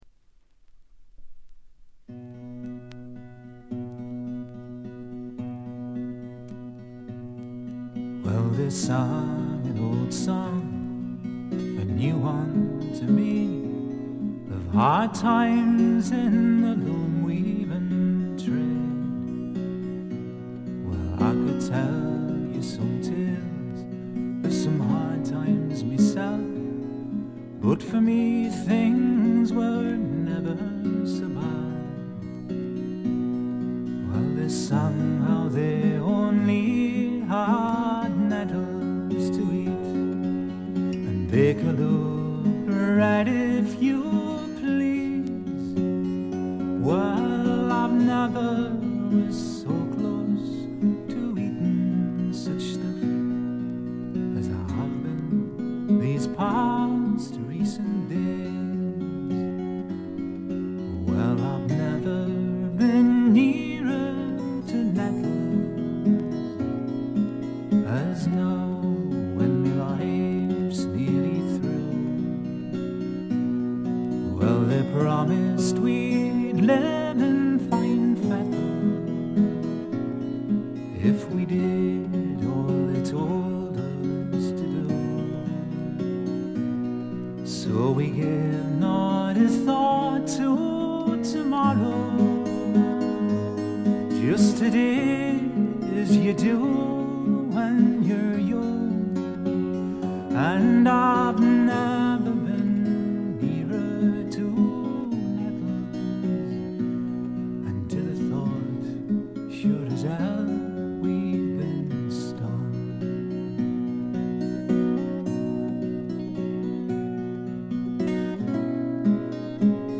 ホーム > レコード：英国 フォーク / トラッド
部分試聴ですがチリプチ少々、散発的なプツ音少々。
試聴曲は現品からの取り込み音源です。